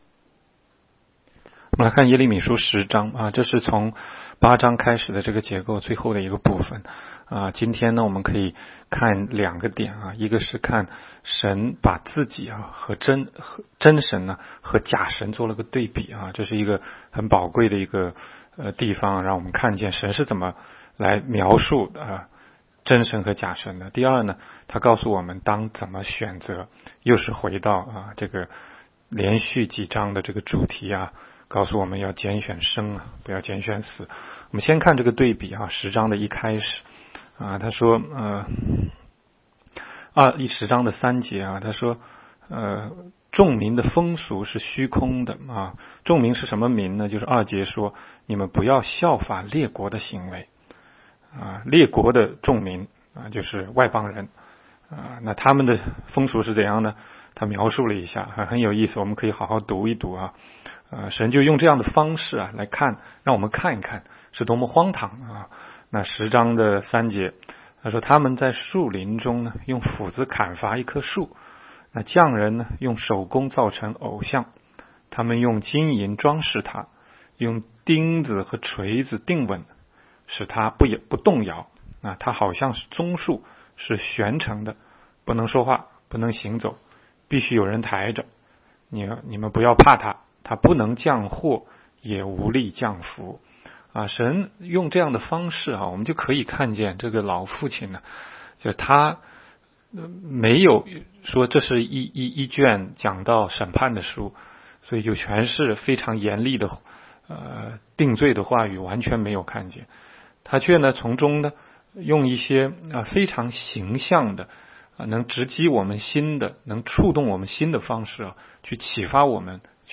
16街讲道录音 - 每日读经 -《耶利米书》10章